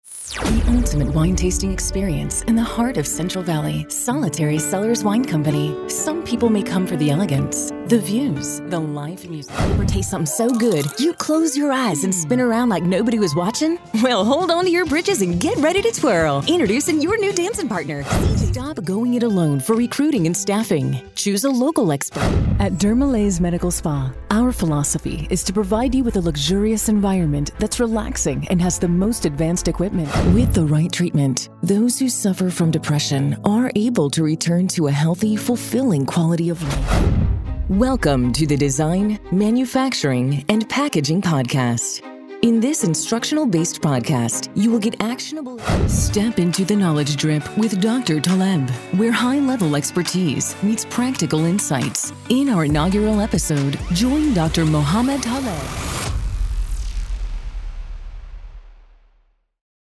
Commercial Demo Reel
Neutral North American
Young Adult